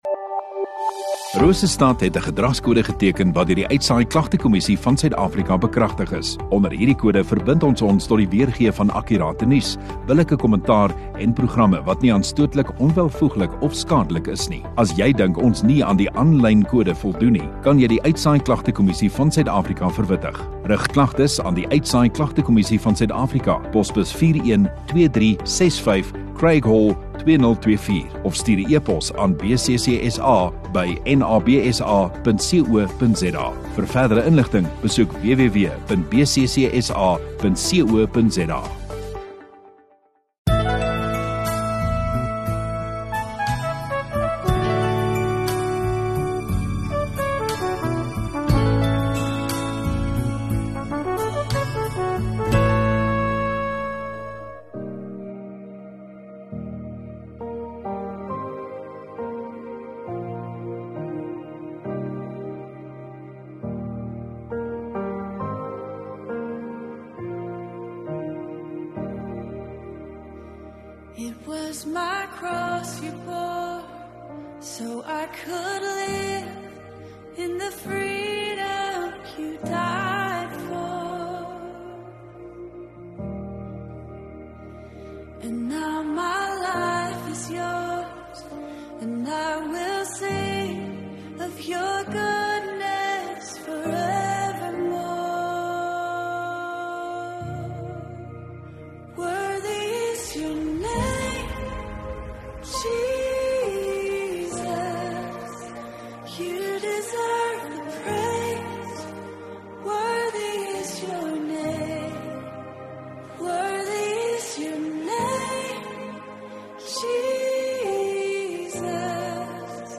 30 Mar Sondagaand Erediens